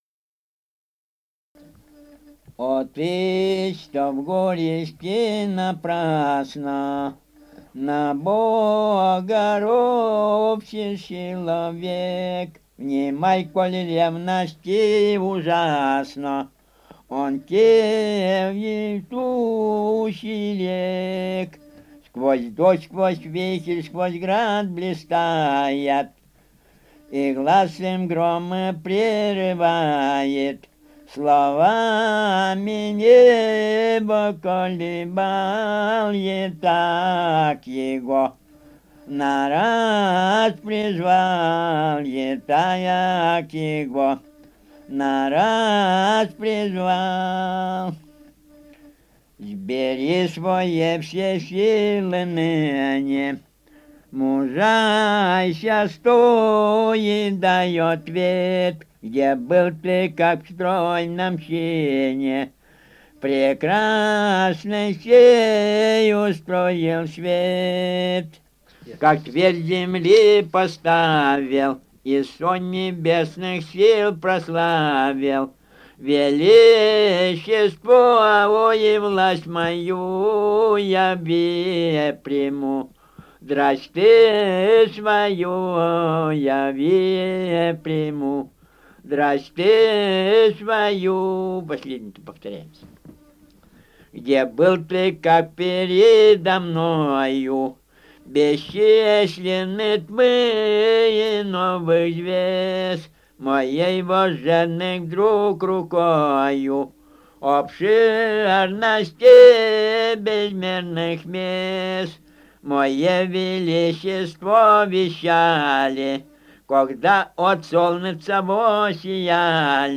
Республика Алтай, Усть-Коксинский район, с. Огнёвка, июнь 1980.